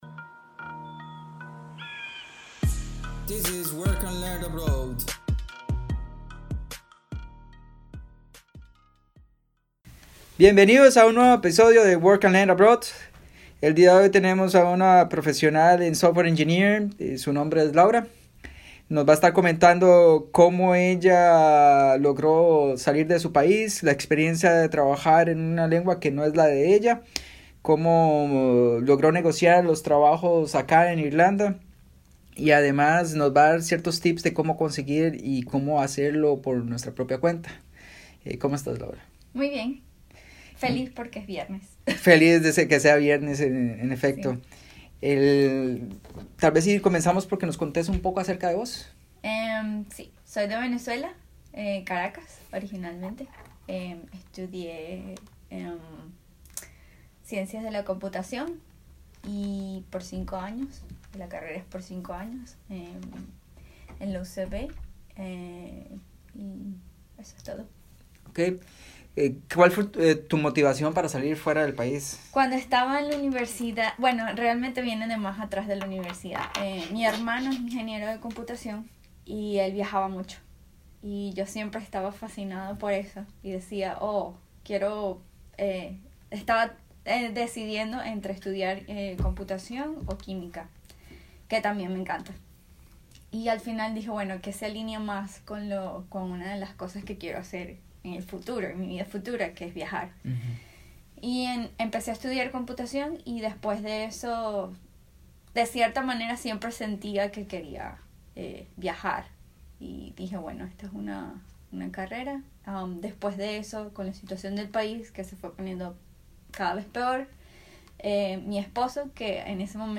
Trabajar en el extranjero Entrevista